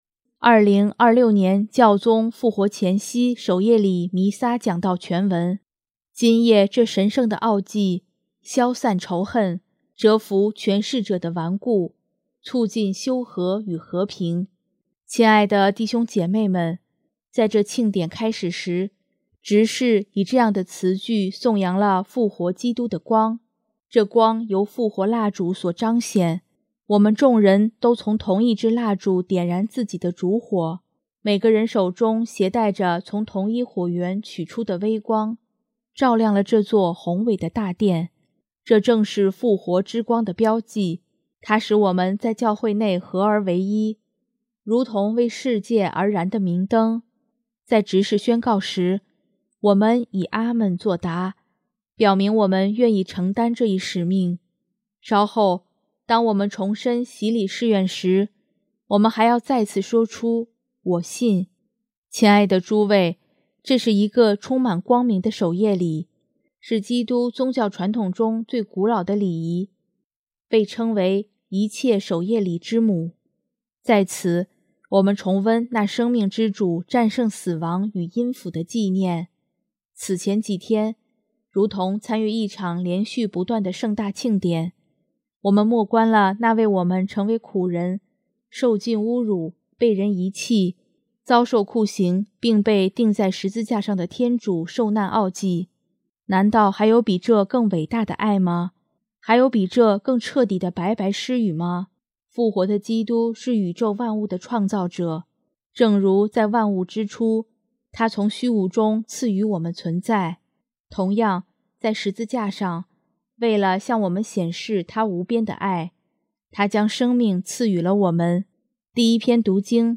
新闻| 2026年教宗复活前夕守夜礼弥撒讲道全文